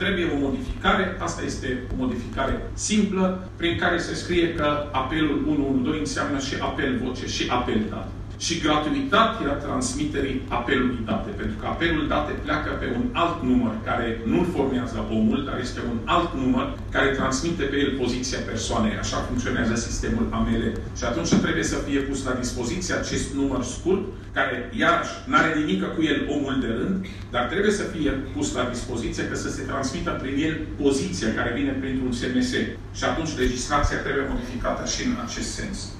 Mai sunt însă şi alte aspecte, a spus Raed Arafat: